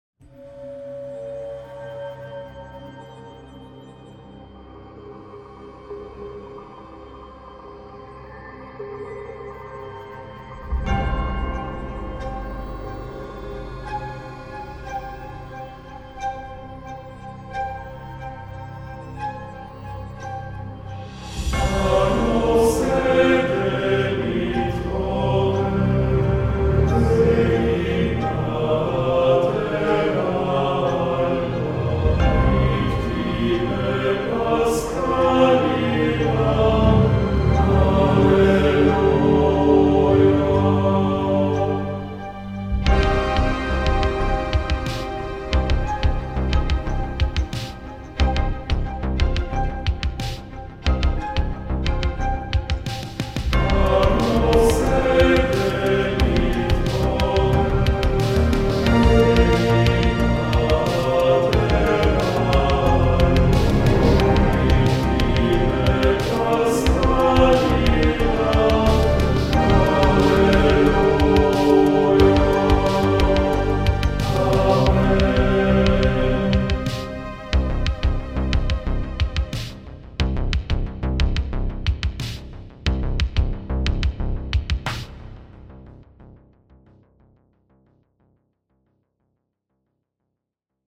这个库使用了全音阶内的一个八度来录制所有的短语，所以你不受特定的速度或调性的限制。
此外，这个库还包含了一个“嗯”音的持续音和呼吸音，可以用来增加真实感。